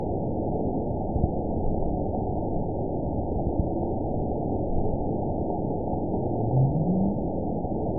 event 920338 date 03/17/24 time 03:13:48 GMT (1 year, 3 months ago) score 9.64 location TSS-AB03 detected by nrw target species NRW annotations +NRW Spectrogram: Frequency (kHz) vs. Time (s) audio not available .wav